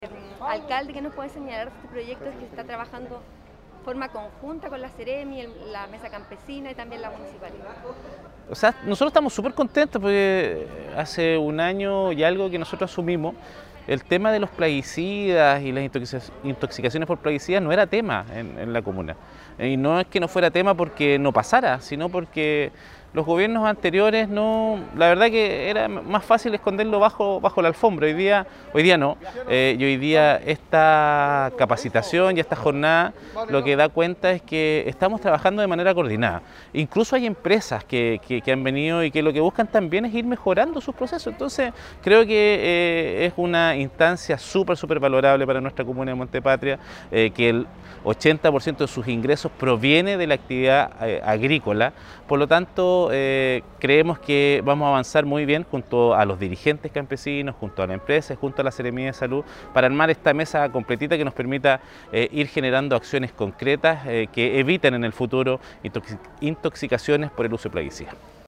AUDIO : Alcalde de Monte Patria, Cristian Herrera